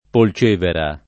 vai all'elenco alfabetico delle voci ingrandisci il carattere 100% rimpicciolisci il carattere stampa invia tramite posta elettronica codividi su Facebook Polcevera [ pol ©% vera ] top. f. (Lig.) — con -e- chiusa l’uso locale; in Tosc. e nel Centro, una certa tendenza alla lettura con -e- aperta — cfr. Val Polcevera